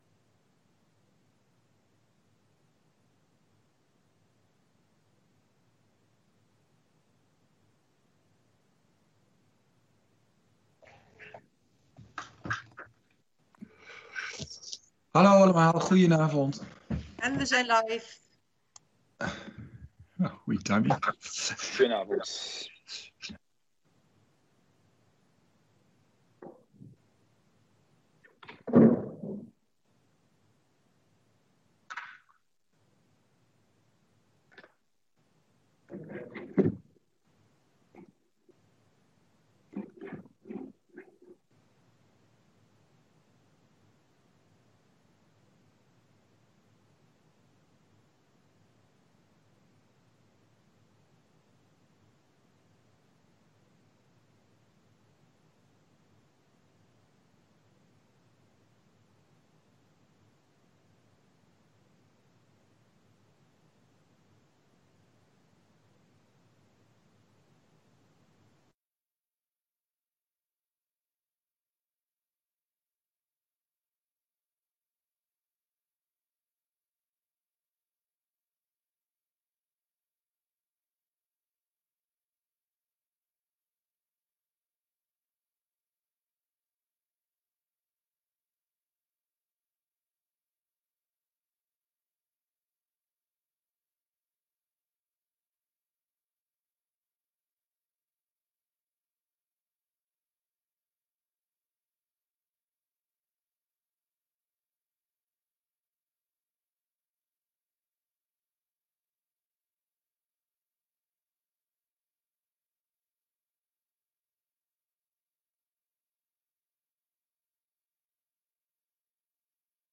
Gemeenteraad 16 december 2020 20:00:00, Gemeente Renkum
Download de volledige audio van deze vergadering
Locatie: Raadzaal